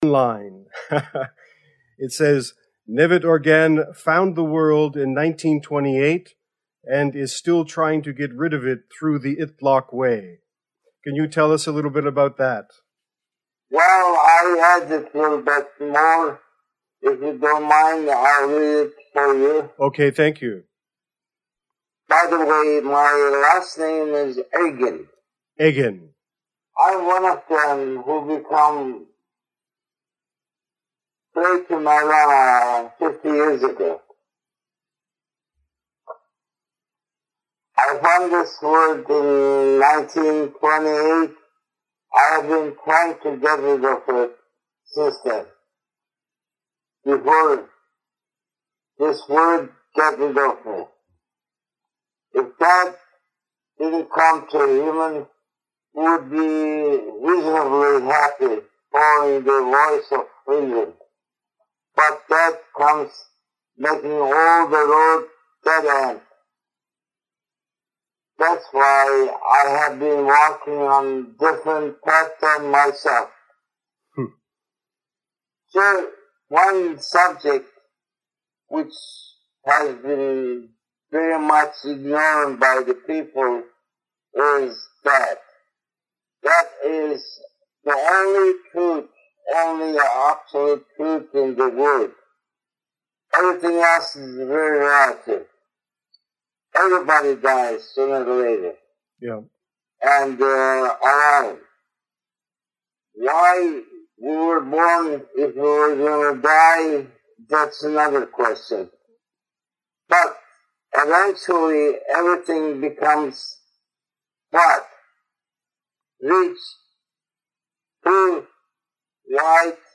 The idiot players podcast Because of the bad quality of the recording